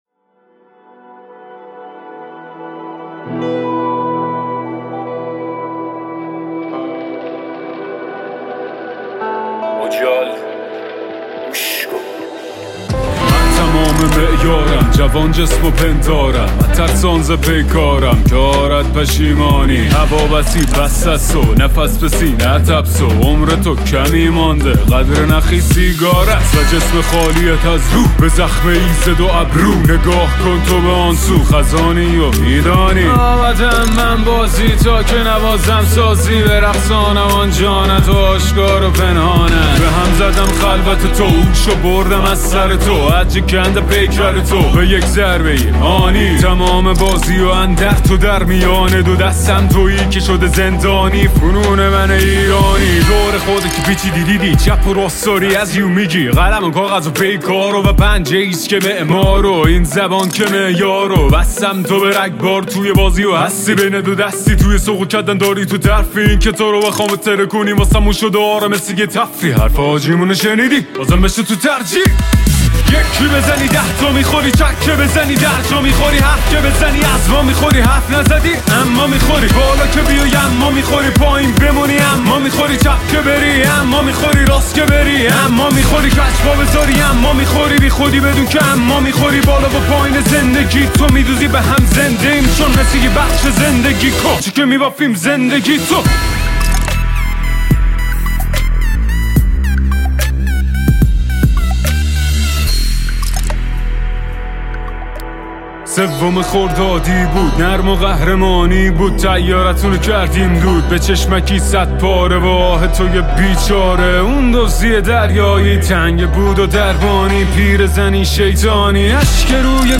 حس اقتدار و ایستادگی را در قالب کلمات و ریتم منتقل کند.